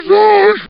zombie_voice_idle3.mp3